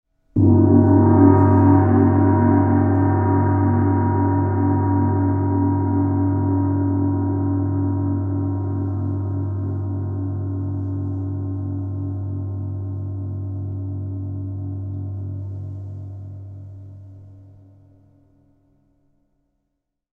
Глубокие вибрации и переливчатые обертоны подойдут для медитации, звукотерапии или создания атмосферы в творческих проектах.
Гонг – Послушайте это долгий